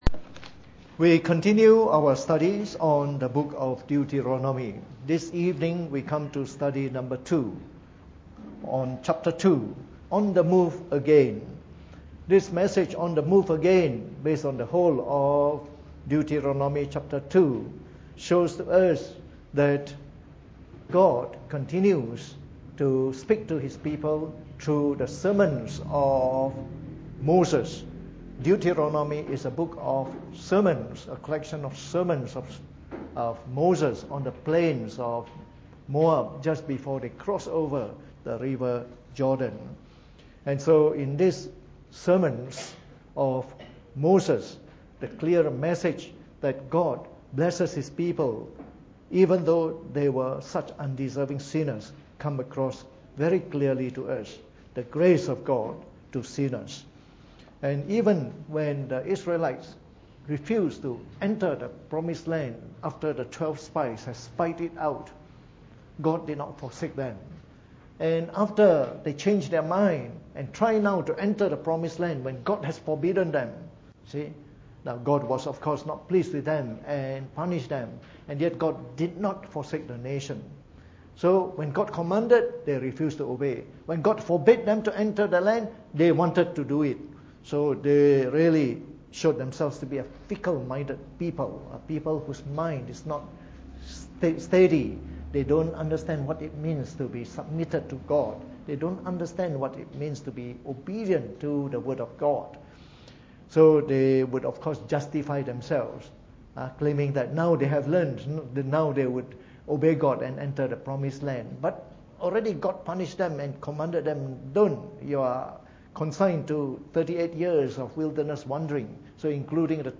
Preached on the 17th of January 2018 during the Bible Study, from our series on the book of Deuteronomy.